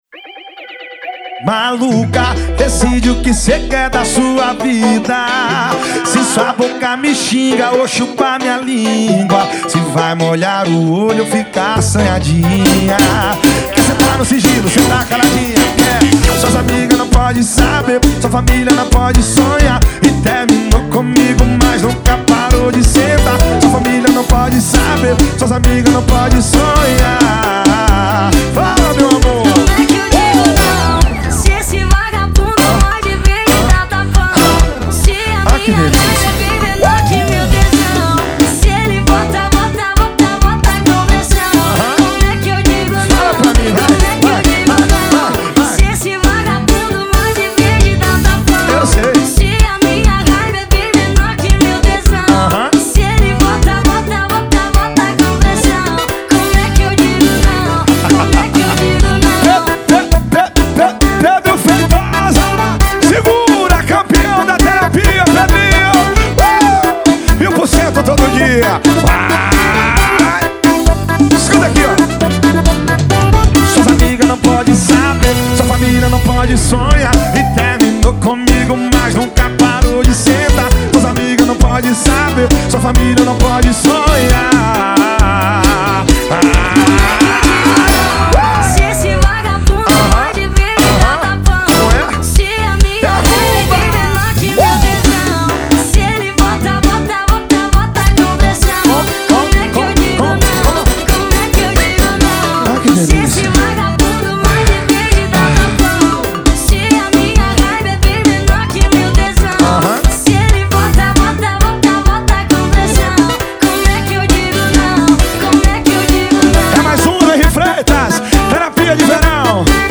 2024-02-14 18:21:05 Gênero: Forró Views